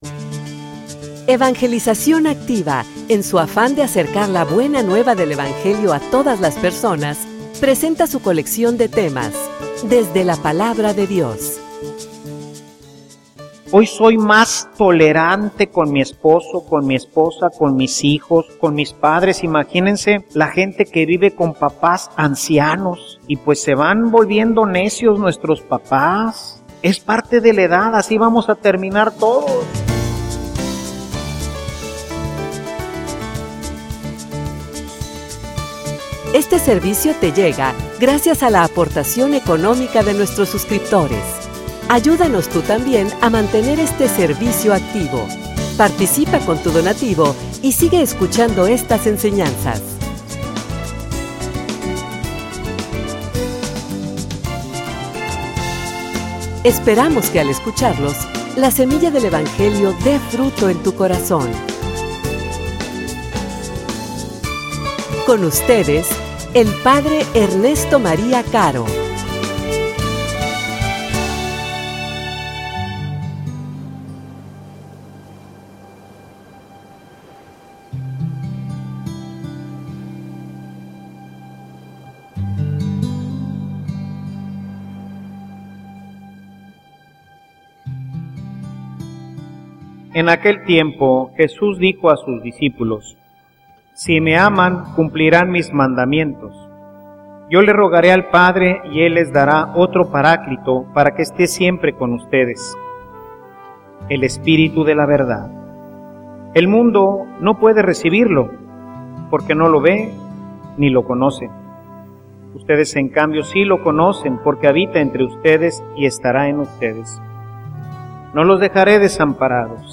homilia_Encuentro_que_nos_lleva_al_amor.mp3